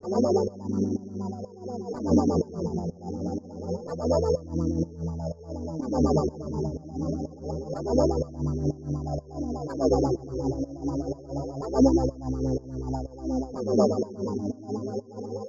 PainPerdu_124_G_FX.wav